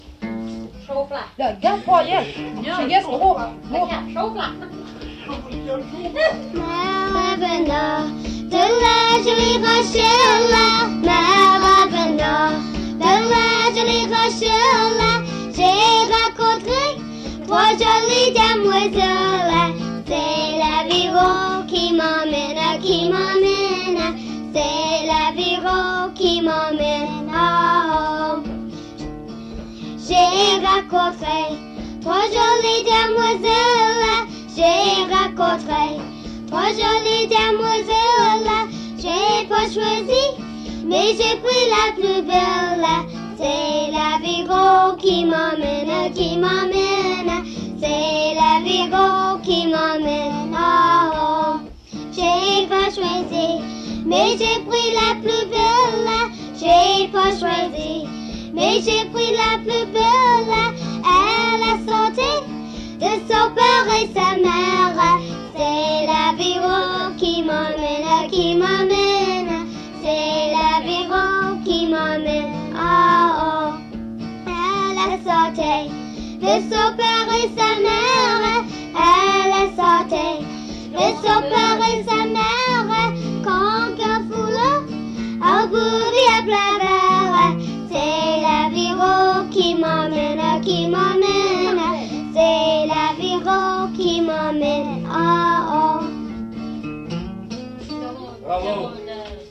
Interprète La famille Cornect
Emplacement La Grand'Terre
Avec guitare